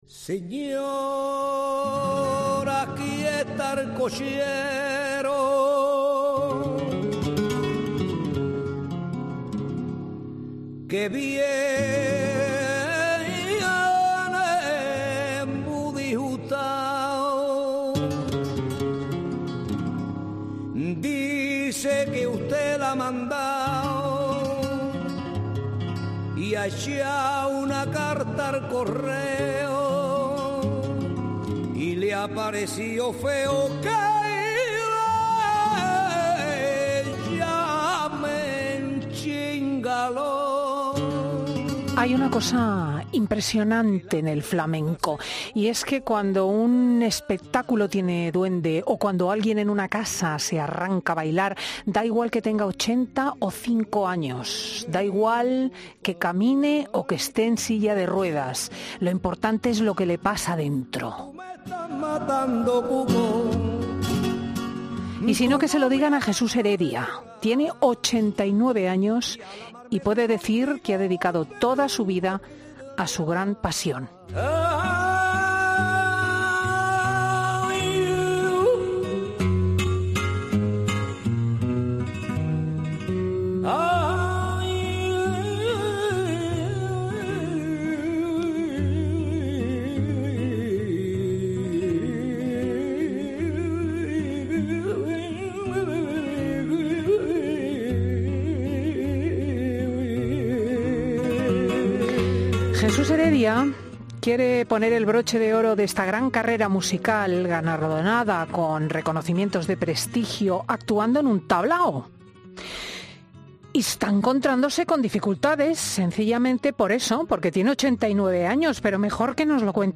para reportajes y entrevistas en profundidad